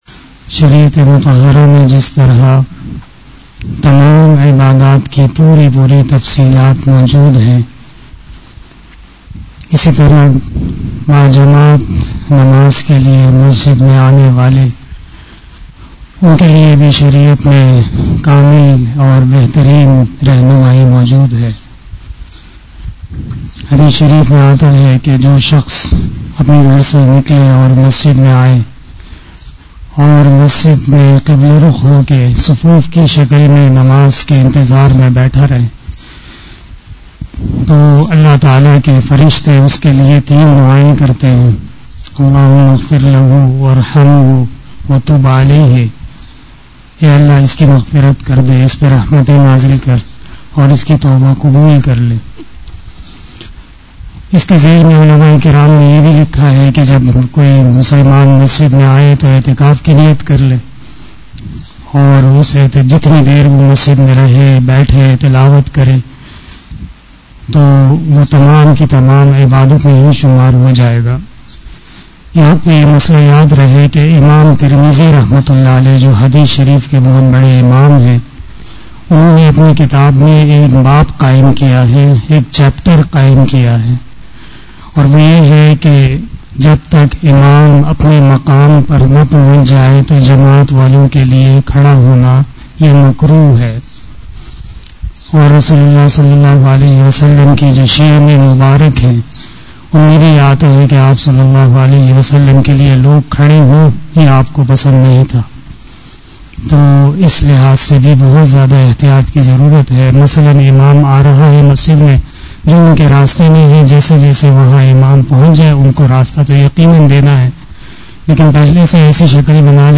Bayan
After Asar Namaz Bayan